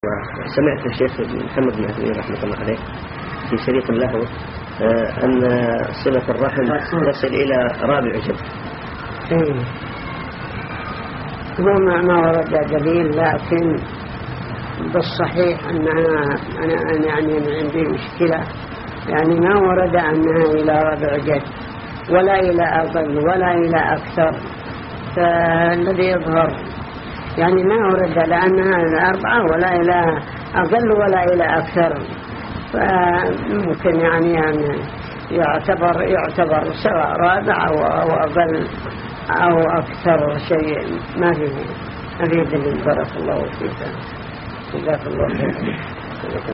| فتاوى الشيخ مقبل بن هادي الوادعي رحمه الله